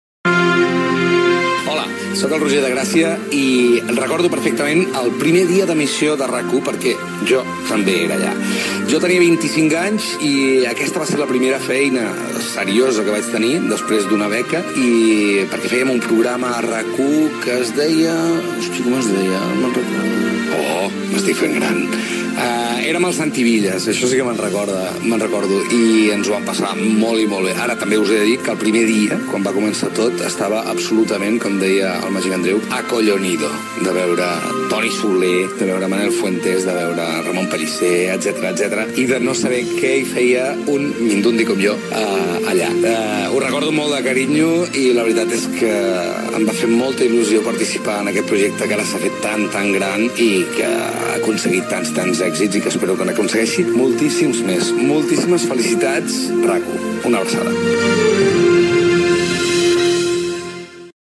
Felicitacions pels 15 anys de RAC 1.